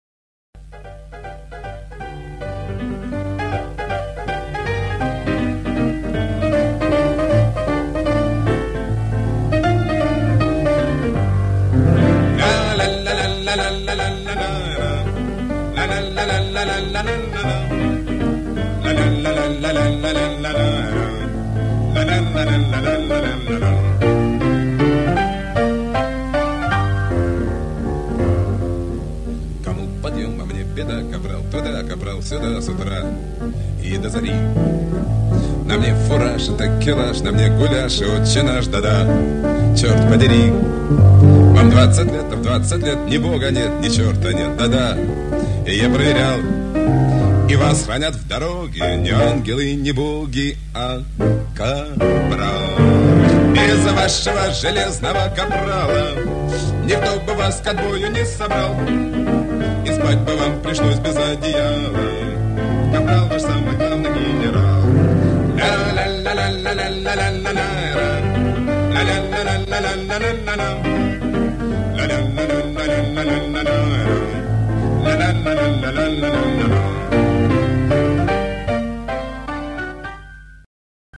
Сначала нашелся один куплет в исполнении автора